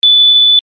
Smoke-alarm-sound-effect.mp3